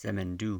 pronounced: seh-men-DOO